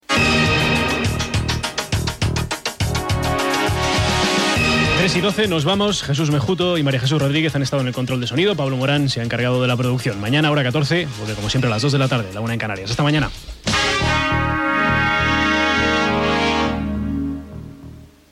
Tancament del programa, amb l'hora, equip i sintonia del programa.